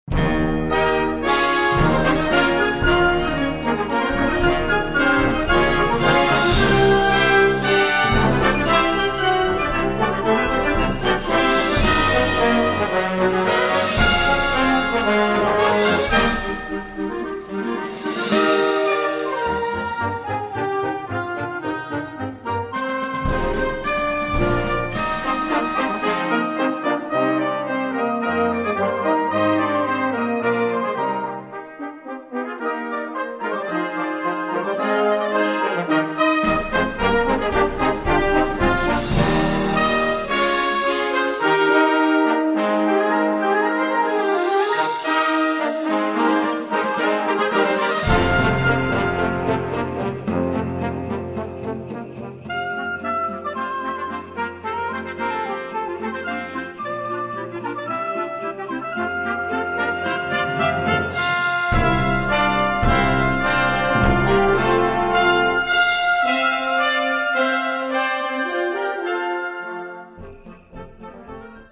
Gattung: Weihnachtliche Musik
Besetzung: Blasorchester
Durchdrungen von Energie, Freude und Lebendigkeit